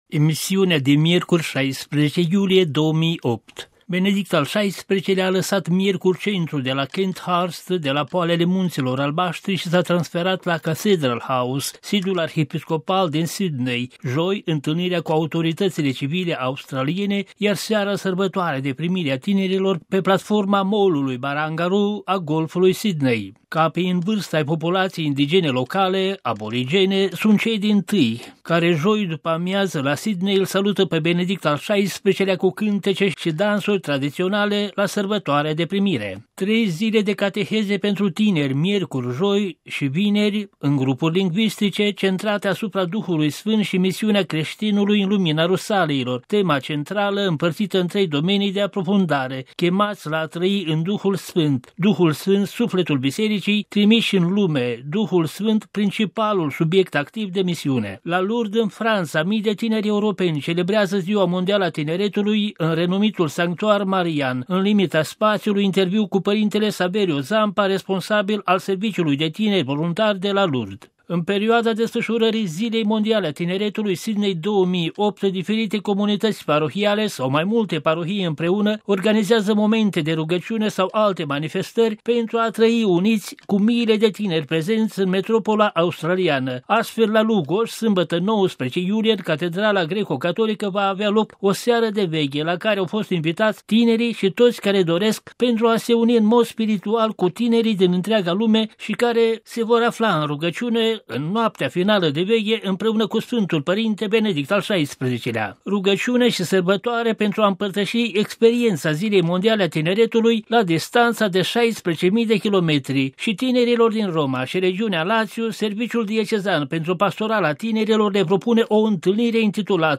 (RV - 16 iulie 2008) Principalele ştiri ale emisiunii şi alte informaţii: